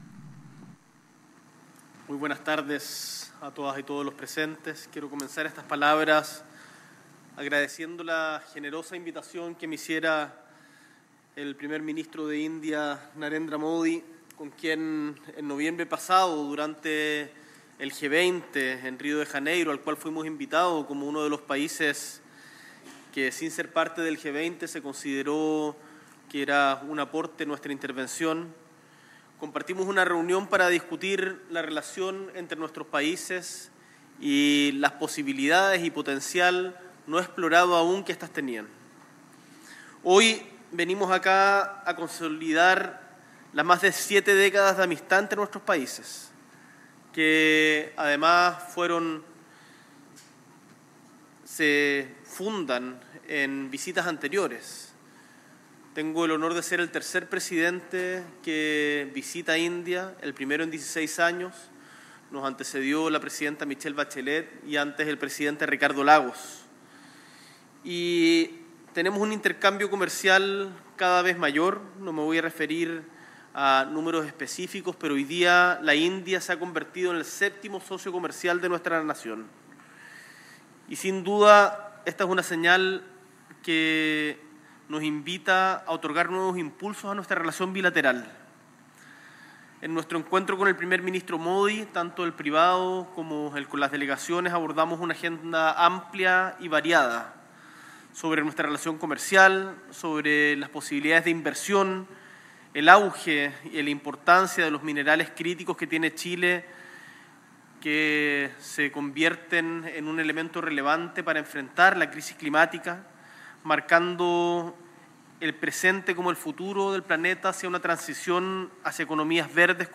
S.E. el Presidente de la República, Gabriel Boric Font, realiza declaración conjunta con el Primer Ministro de India, Narendra Modi.